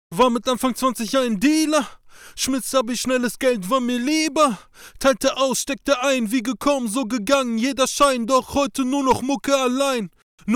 Hier eine Testaufnahme Kopfhörer sind Dt770 Pro